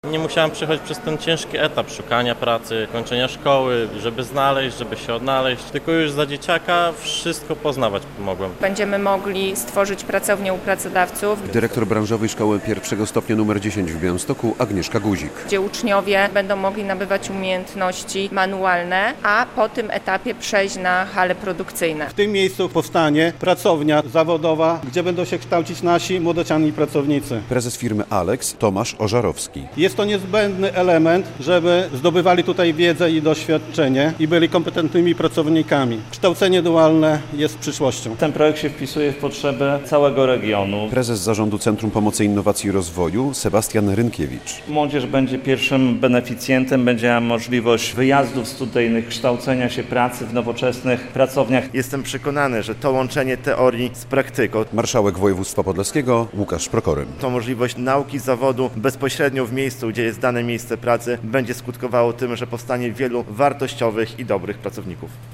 200 uczniów skorzysta z praktycznej nauki zawodu we współpracy z firmami - relacja